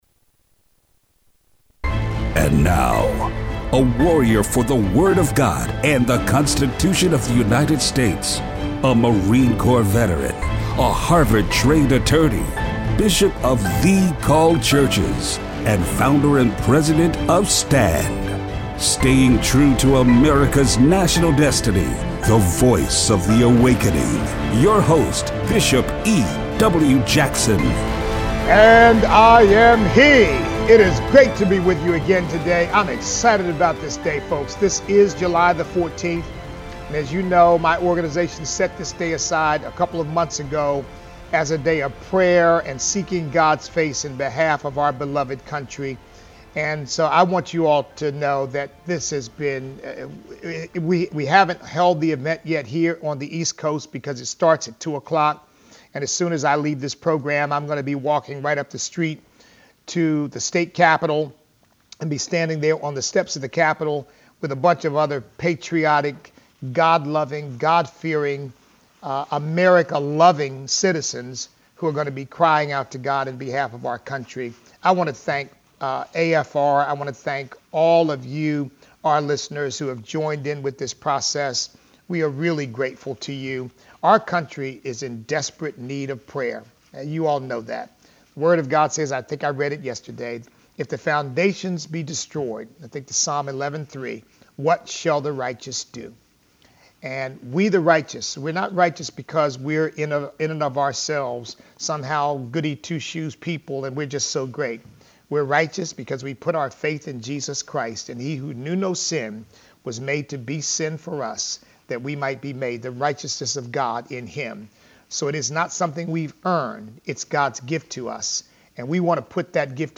Show Notes Our listeners call in to pray for our nation.